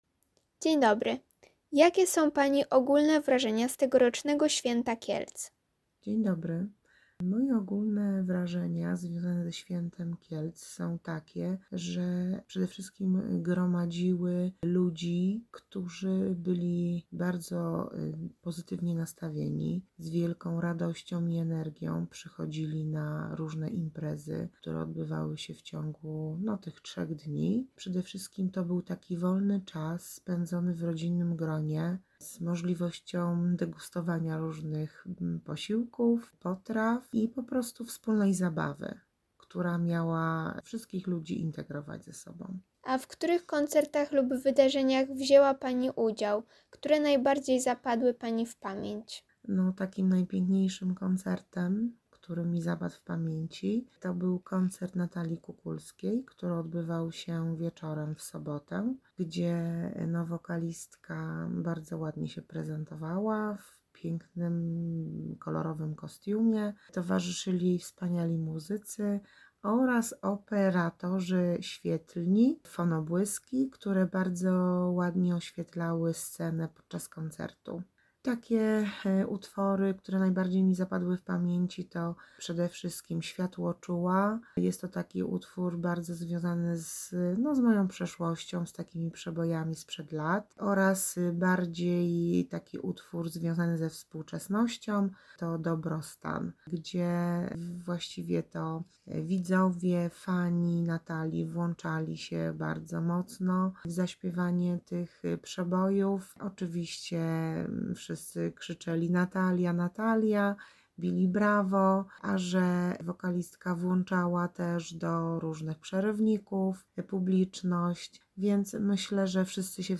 Co o tegorocznym Święcie Kielc sądzą jego uczestnicy? Posłuchajcie krótkiej rozmowy z kielczanką, która podzieliła się swoimi wrażeniami:
Wywiad.mp3